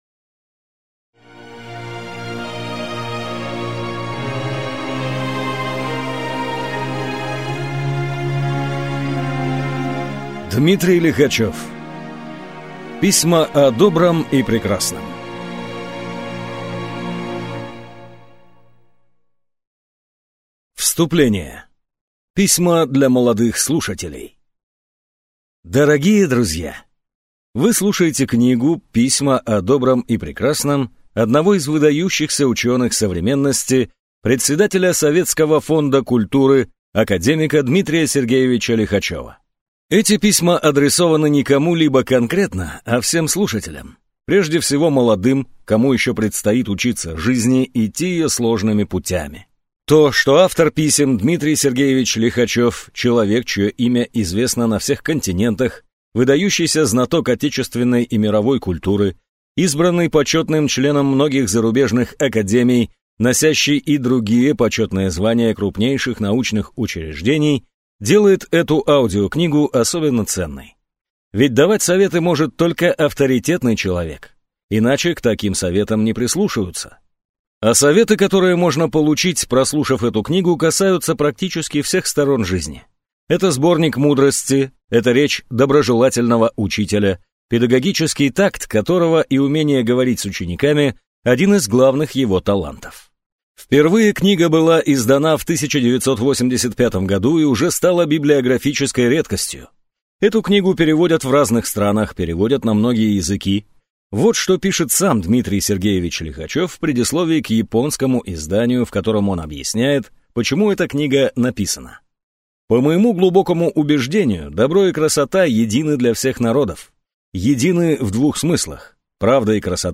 Аудиокнига Письма о добром и прекрасном | Библиотека аудиокниг
Прослушать и бесплатно скачать фрагмент аудиокниги